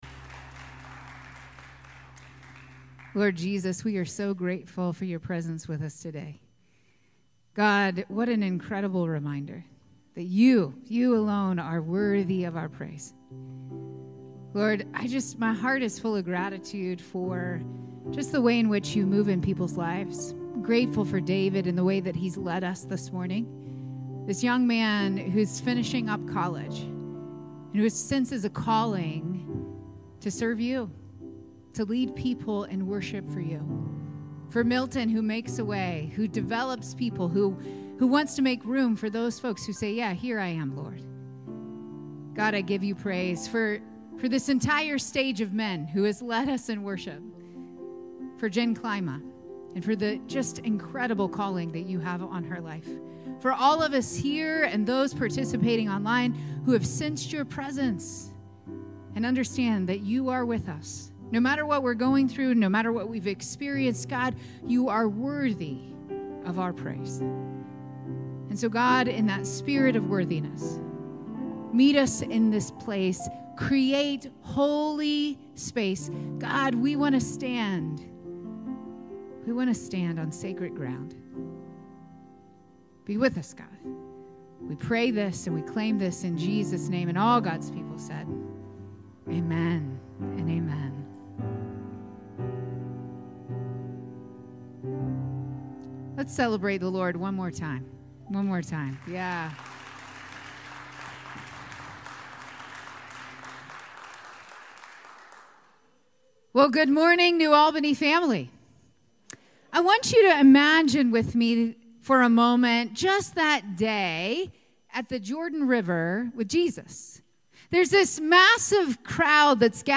July 21, 2024 Sermon